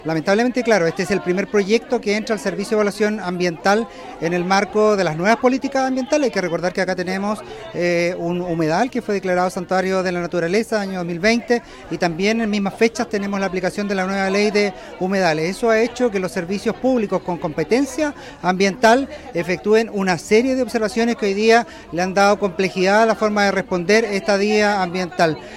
Por su parte, el seremi indicó que dadas las nuevas normativas vigentes en materias medioambientales, la entidad patrocinante debe responder a las observaciones realizadas por el Servicio de Evaluación Ambiental.